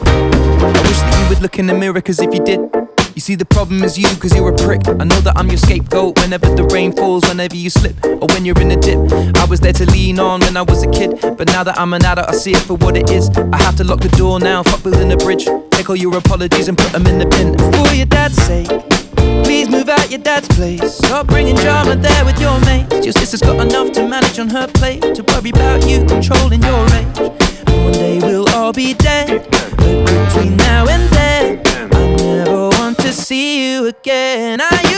Pop Singer Songwriter
Жанр: Поп музыка